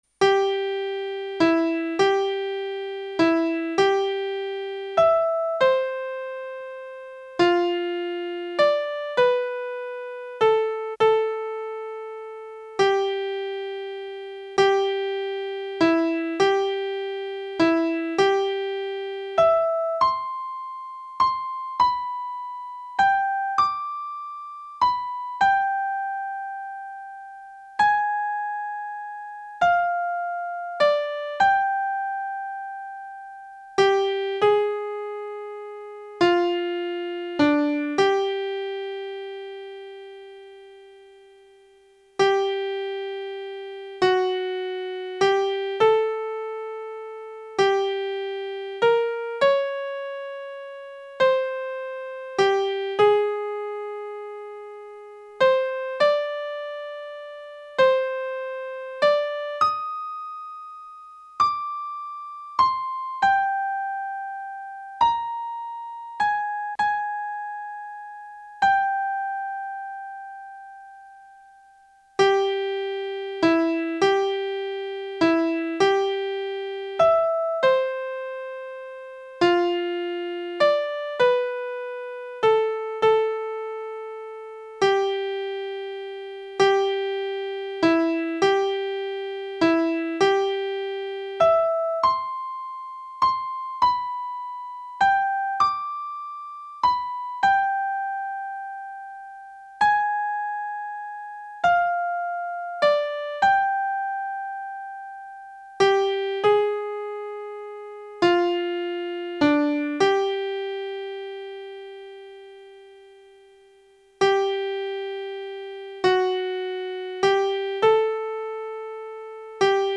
Помогите, пожалуйста, определить название одной мелодии, по нижеприведённому примерному наигрышу! (Как всегда у меня - "схематично", одной рукой:)
А оригинальный был - медленнее, чуть более чем вдвое: